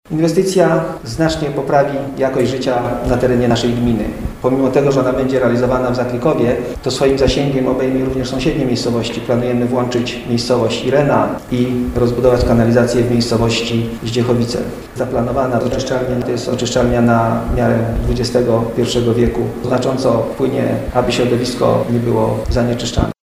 Rozbudowa oczyszczalni pozwoli podłączyć do kanalizacji kolejne miejscowości. Mówi burmistrz Dariusz Toczyski: